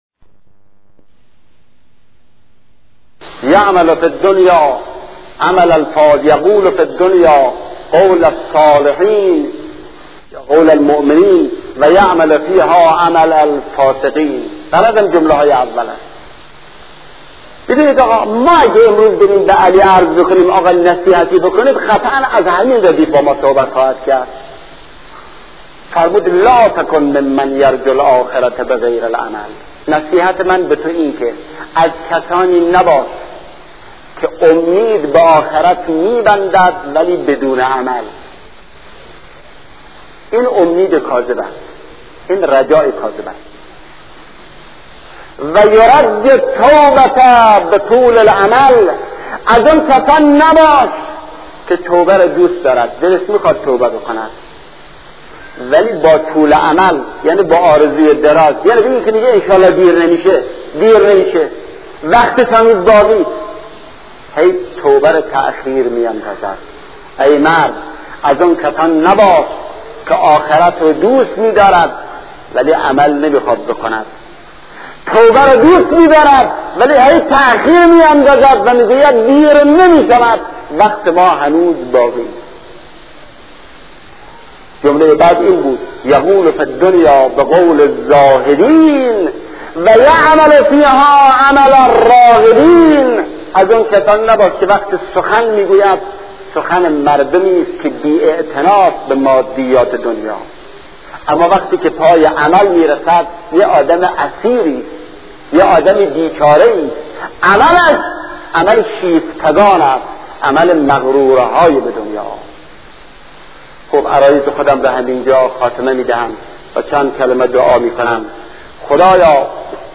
سخرانی